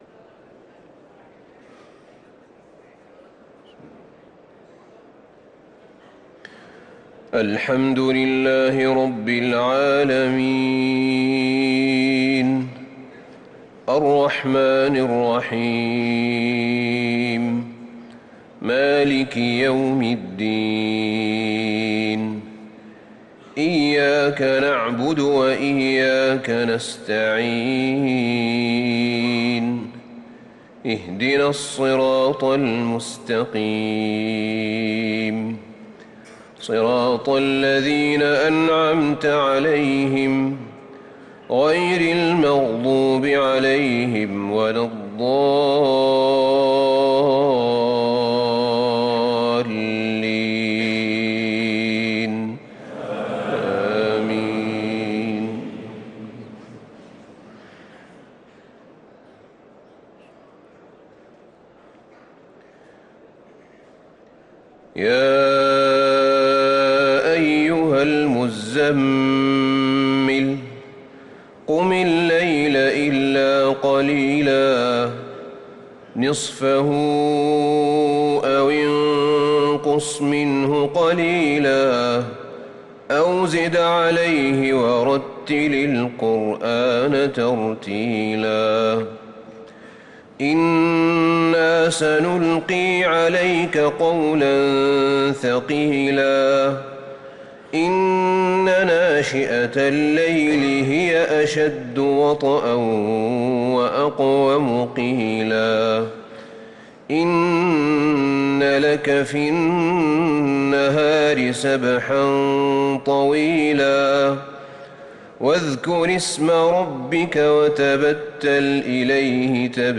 صلاة الفجر للقارئ أحمد بن طالب حميد 16 جمادي الأول 1445 هـ
تِلَاوَات الْحَرَمَيْن .